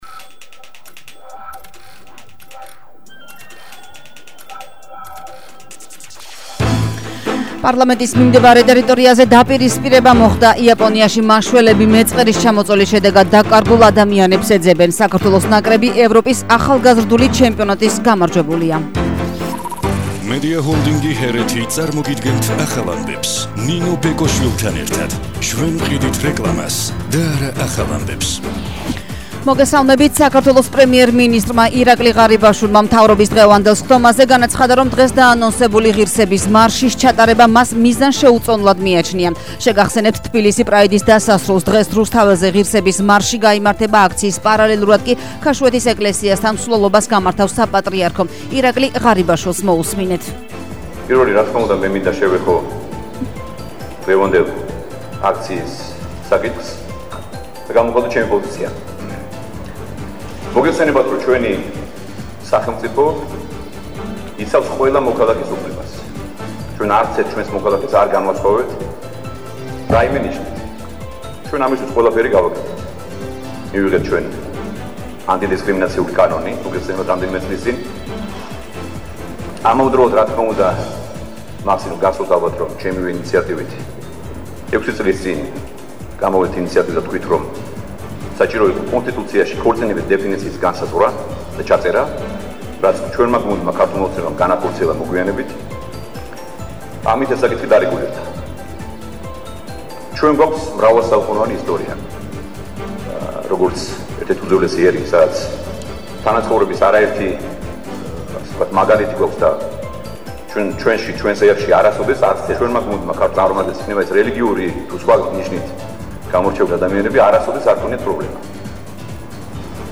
ახალი ამბები 12:00 საათზე –05/07/21 – HeretiFM